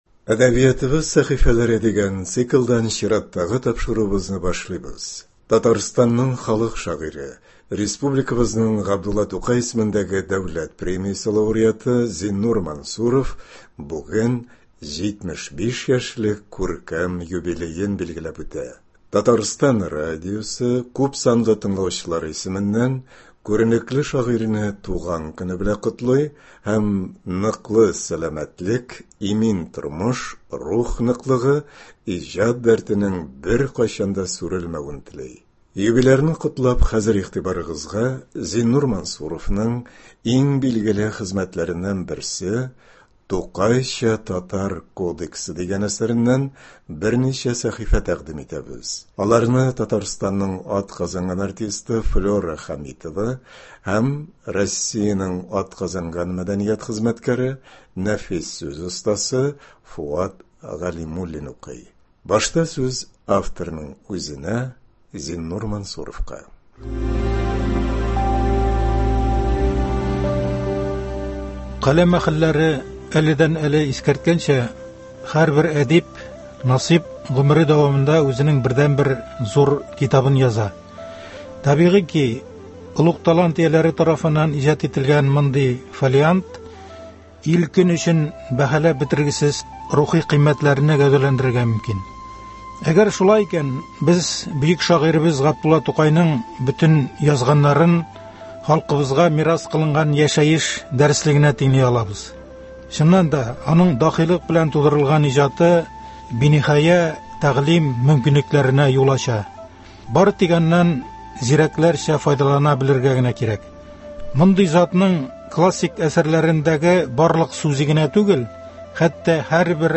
Радиокомпозиция (15.07.24) | Вести Татарстан